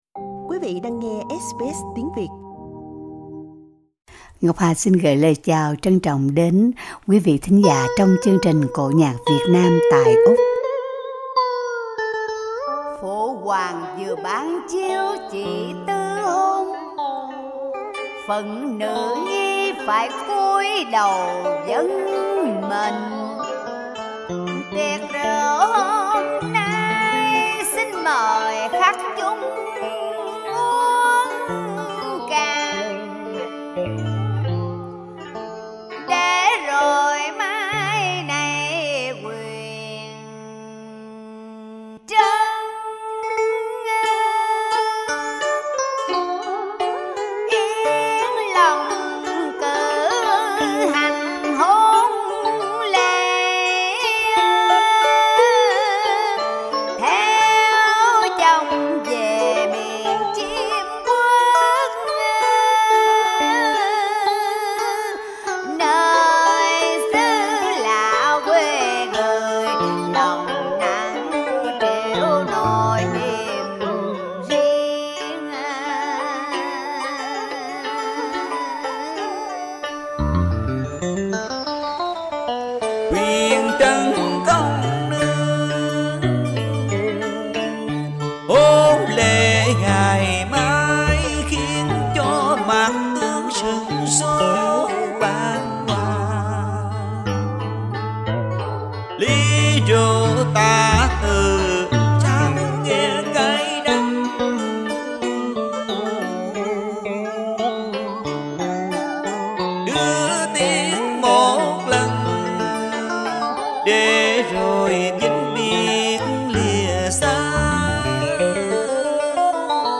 Giọng ca Vọng cổ